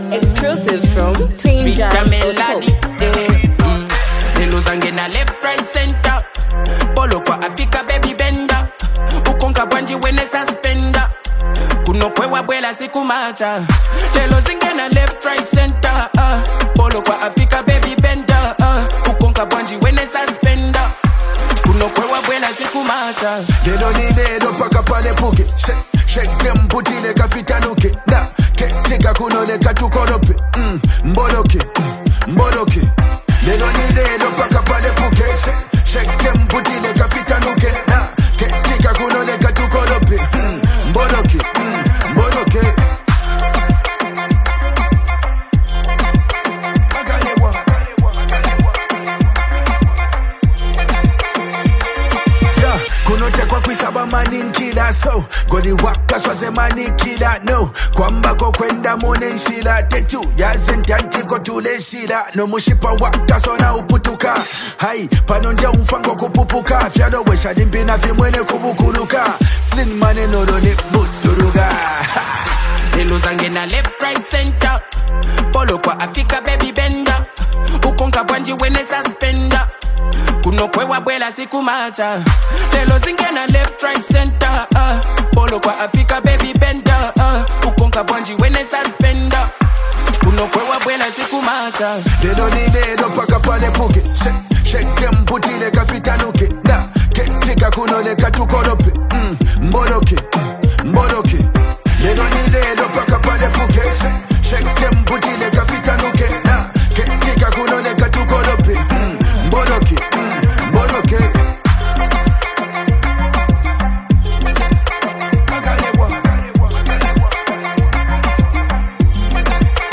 an emotional and heartfelt song
smooth and emotional vocals